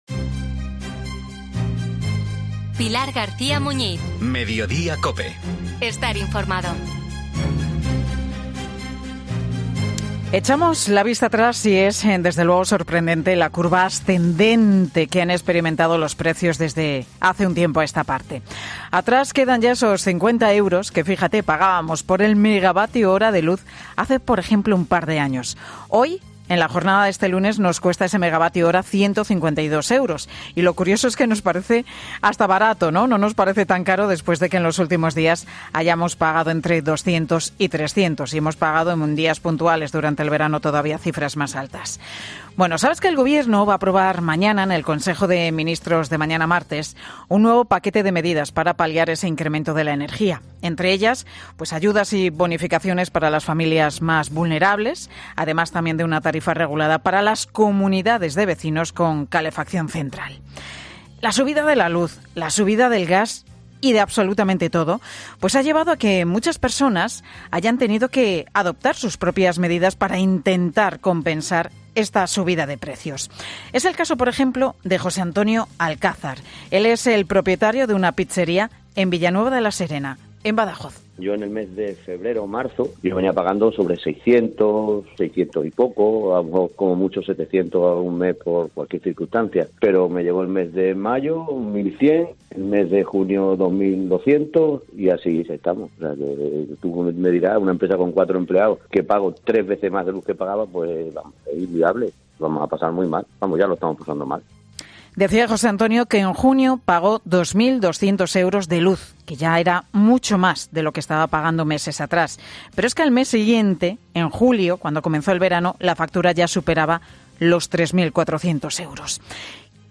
Hasta que comenzaron las subidas de precios, de la luz, de la harina, era un negocio rentable, pero desde que la electricidad está por las nubes y la harina a precio de oro, el futuro es bastante negro: "Al final tendré que despedir gente, si tengo en el finde a dos extras, me tendré que quedar con uno; si tengo dos cocineros, me tendré que quedar con uno" decía con tristeza en Mediodía COPE.